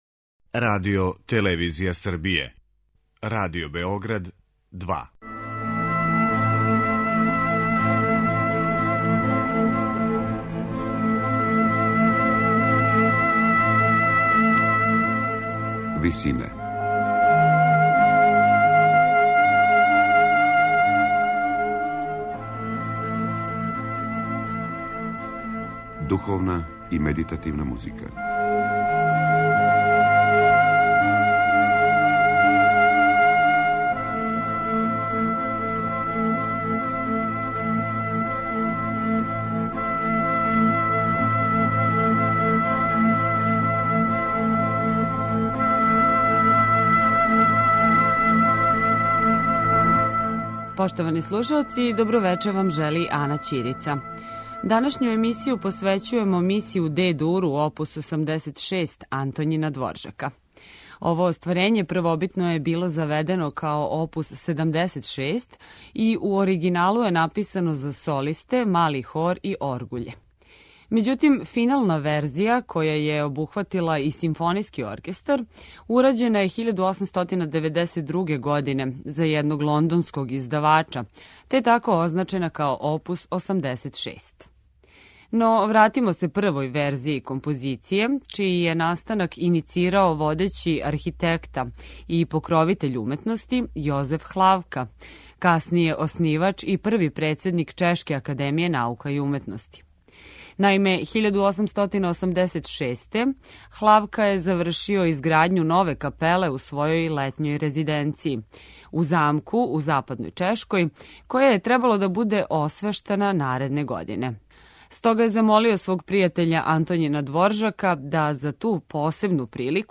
Емисија је посвећена остварењу које је у оригиналу написано за солисте, мали хор и оргуље и првобитно било заведено као оп. 76. Међутим, финална верзија, која је обухватила и симфонијски оркестар, урађена је 1892. године за једног лондонског издавача, те је Миса у Де-дуру означена као оп. 86...